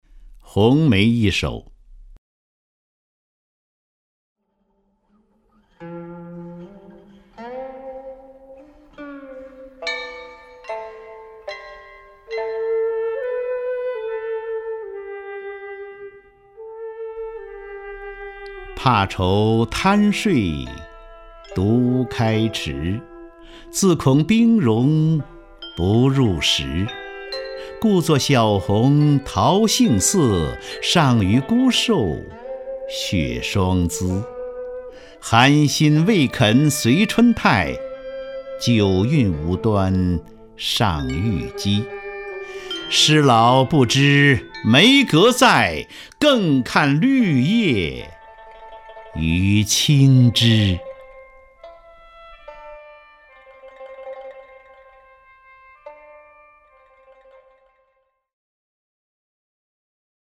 首页 视听 名家朗诵欣赏 张家声
张家声朗诵：《红梅三首·其一》(（北宋）苏轼)